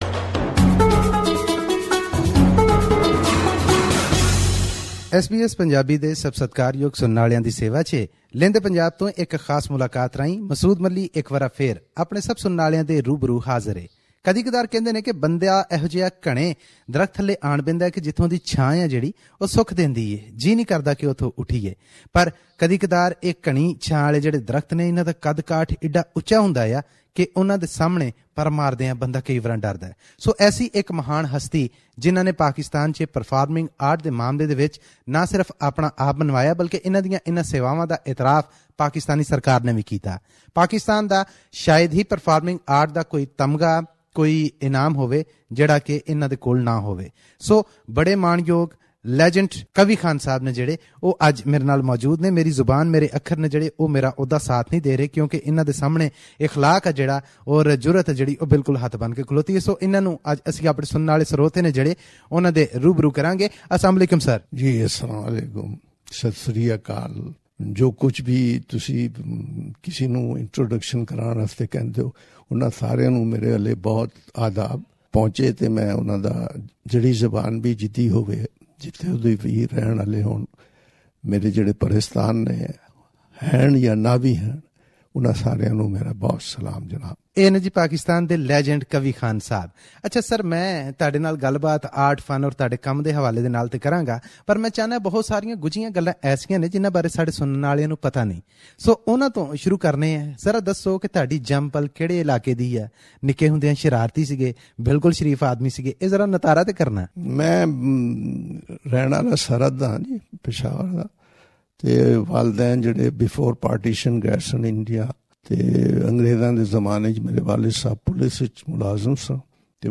Here is an interview with actor, director who appeared first time not only on Pakistani TV but on on South Asia TV as well. He acted in in-numerous Pakistani TV dramas, many movies, and spent almost 53 years in this field.